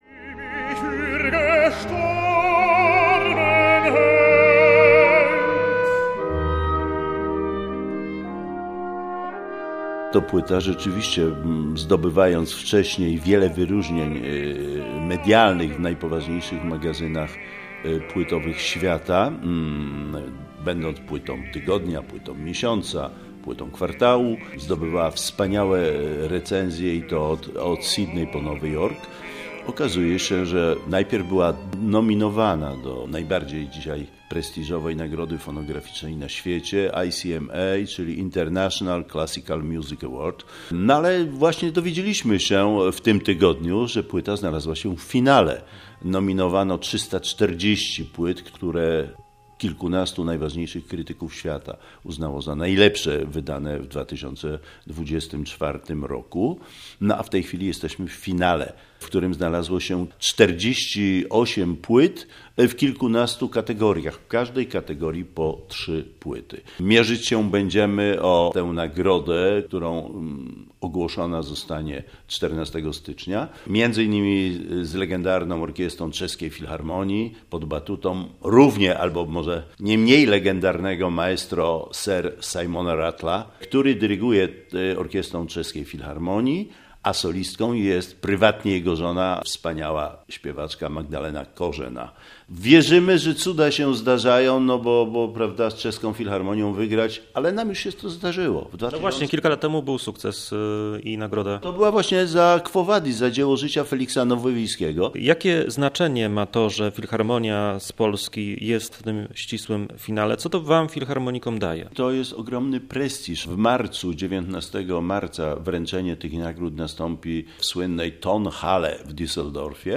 NA ANTENIE: Serwis informacyjny